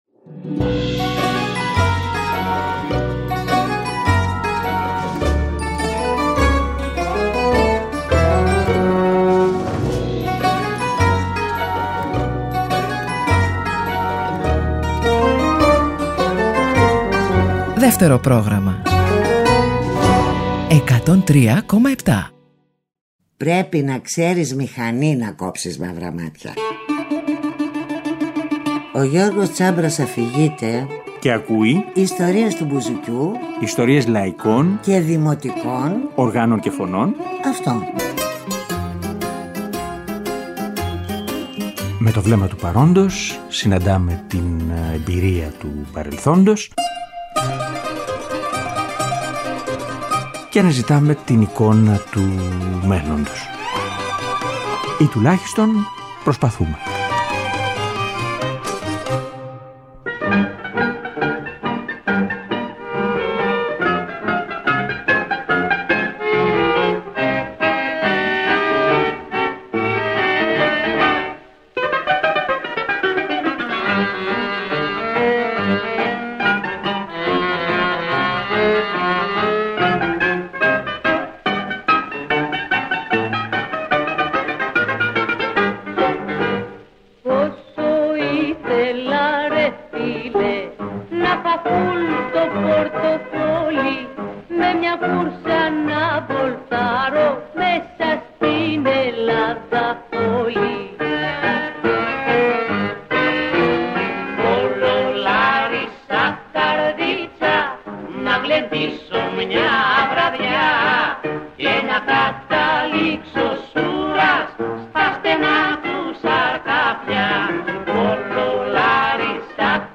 Τρείς ενότητες εποχής γραμμοφώνου και μαζί λίγες νεότερες ηχογραφήσεις.